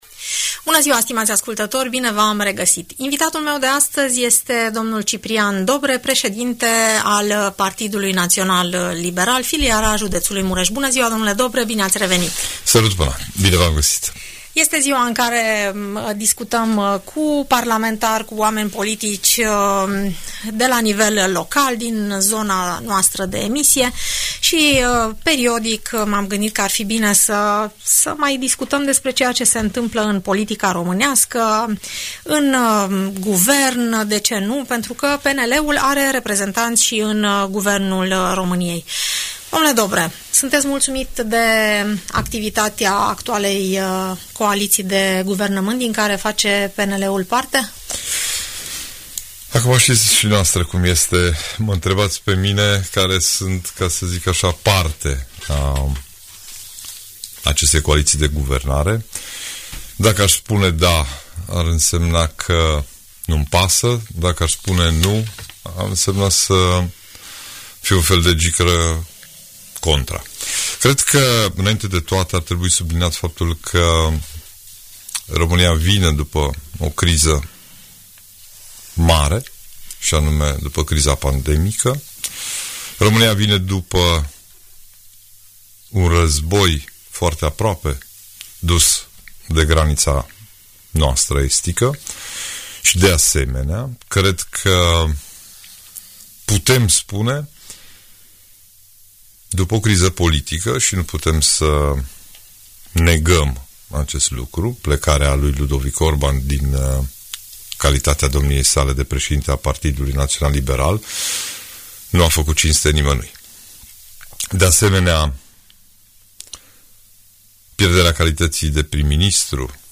Președintele Partidul Național Liberal filiala județului Mureș, dl. Ciprian Dobre, răspunde întrebărilor adresate de ascultătorii emisiunii „Părerea ta”
Audiență radio cu președintele PNL Mureș, dl Ciprian Dobre